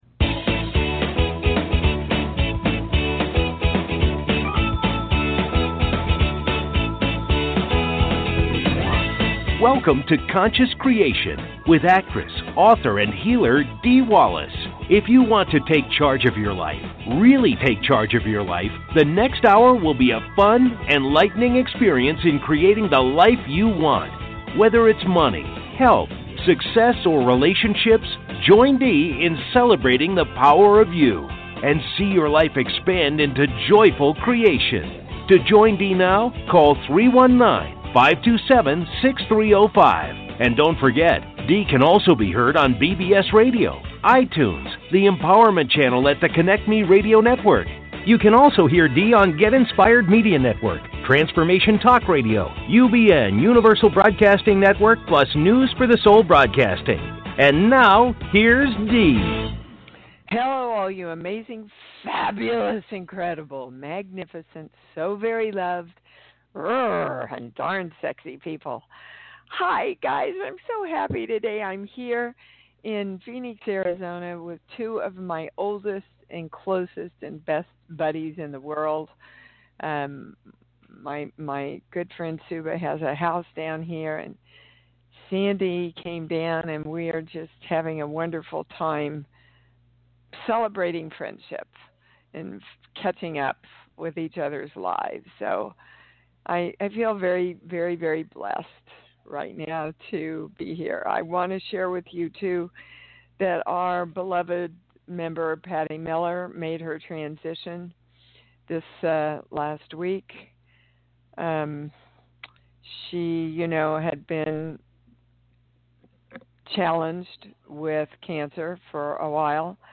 Talk Show Episode, Audio Podcast, Conscious Creation and with Dee Wallace on , show guests , about Dee Wallace,Spiritual Readings,Core Truths,Balanced Life,Energy Shifts,Spiritual Memoir,Healing Words,Consciousness,Self Healing,Teaching Seminars, categorized as Courses & Training,Kids & Family,Paranormal,Philosophy,Motivational,Spiritual,Access Consciousness,Medium & Channeling,Psychic & Intuitive